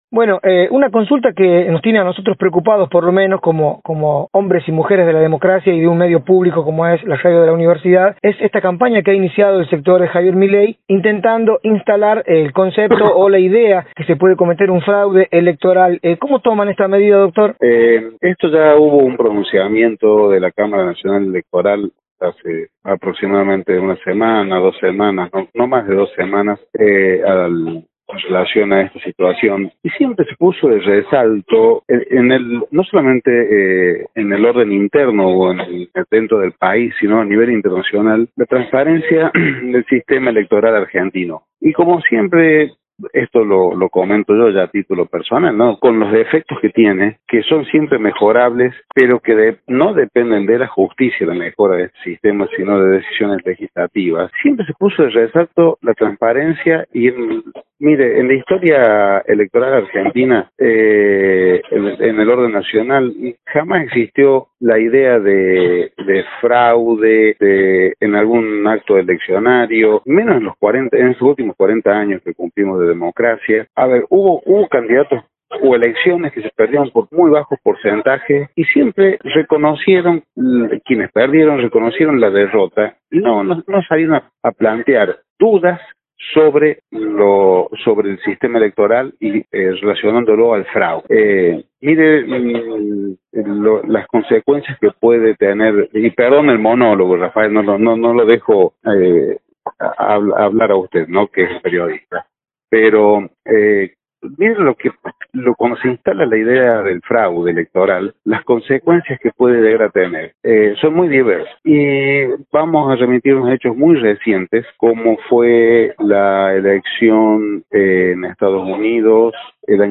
Así lo expresó en diálogo con Radio UNLaR el Dr Víctor Herrera, Secretario Nacional Electoral de La Rioja, quien se refirió a la advertencia de fraude electoral que comenzó desde el sector de LLA.